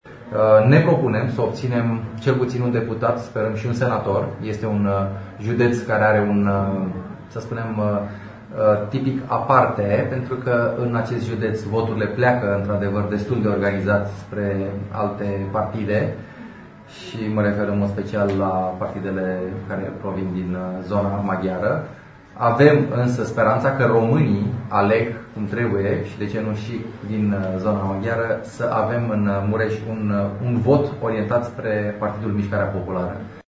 PMP își propune să obțină cel puțin 10% din voturi la alegerile parlamentare, a afirmat azi, într-o conferință de presă desfășurată la Tg.Mureș, președintele executiv al formațiunii, Valeriu Steriu.